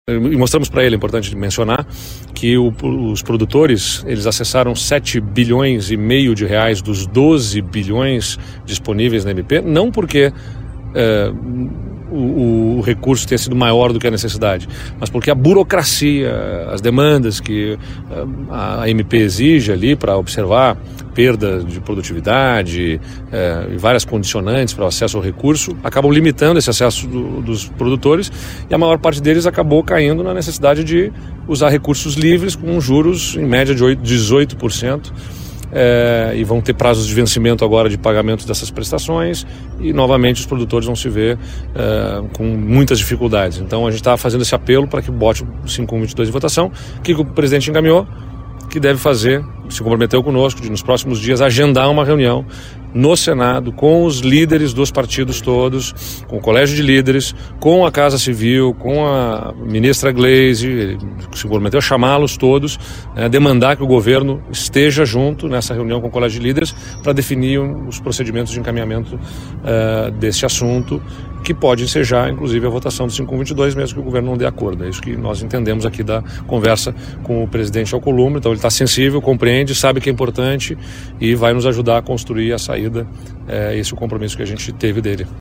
SONORA EDUARDO LEITE: